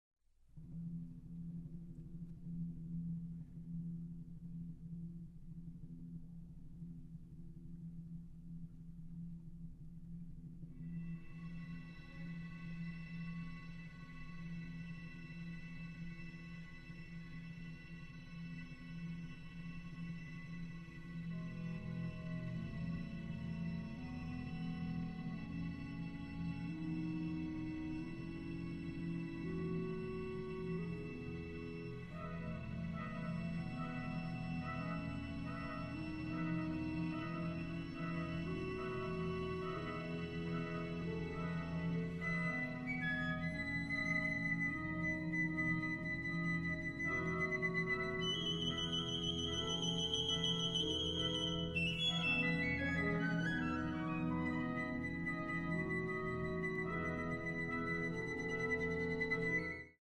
Los tres grupos de oyentes, en Arkansas, Michigan y Dimen, escucharon los mismos 32 estímulos musicales: fragmentos de 60 segundos de música instrumental, la mitad de música occidental y la otra mitad de música china, todos sin letra.